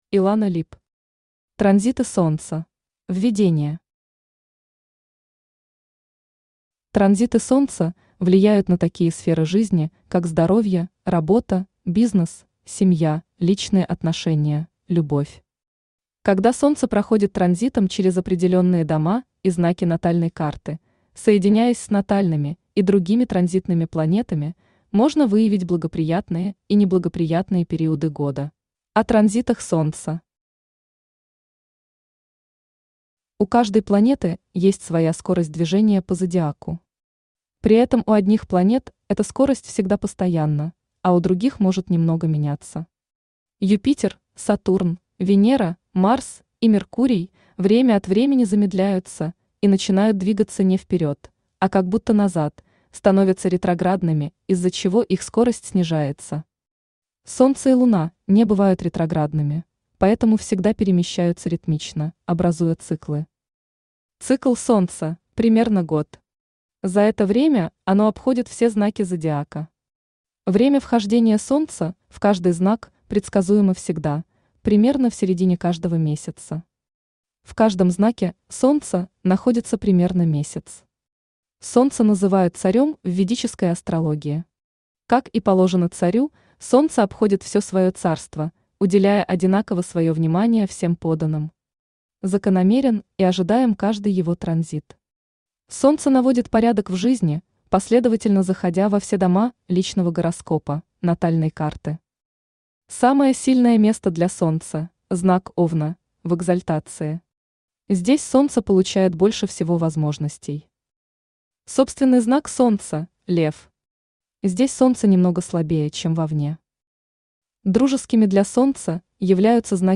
Aудиокнига Транзиты Солнца Автор Илана Либ Читает аудиокнигу Авточтец ЛитРес.